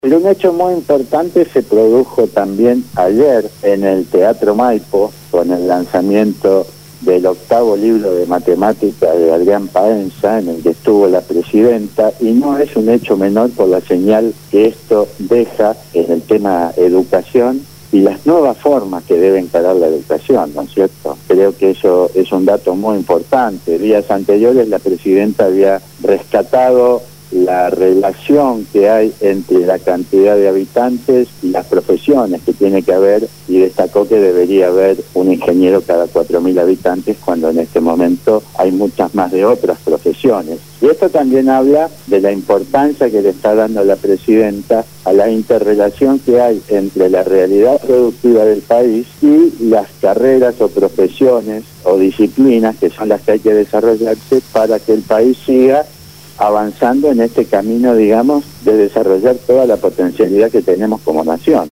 Columna